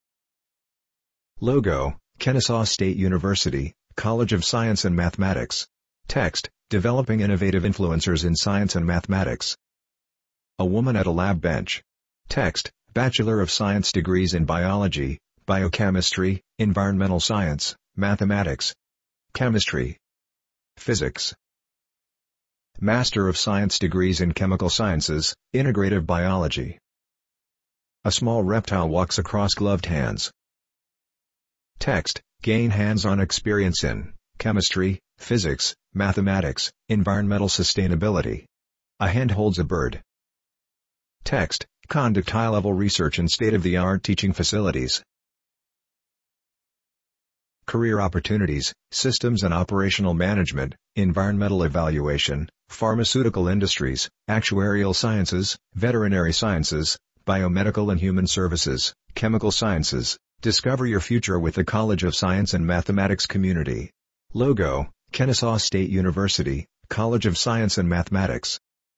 Audio description for the embedded video